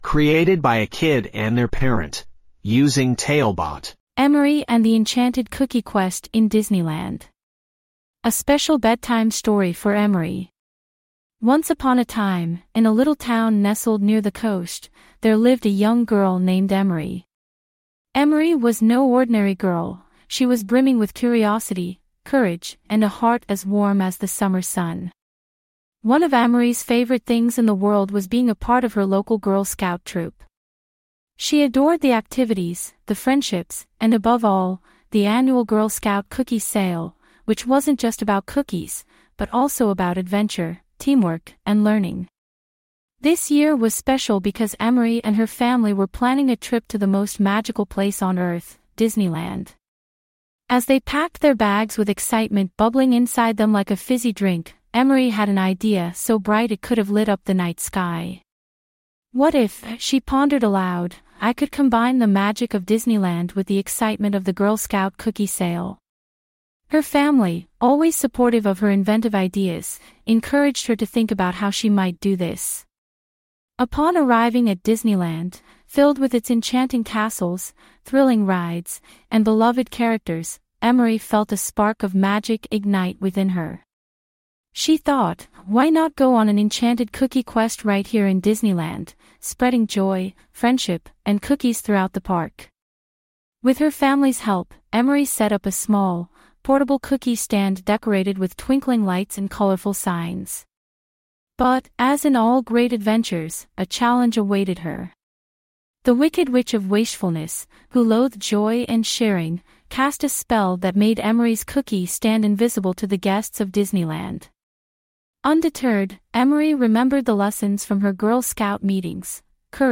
5 minute bedtime stories.
TaleBot AI Storyteller
Write some basic info about the story, and get it written and narrated in under 5 minutes!